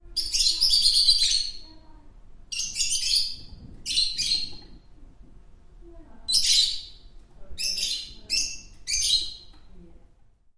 描述：走在高高的草丛中
Tag: 鸟鸣声 气氛 户外 线索 自然 现场录音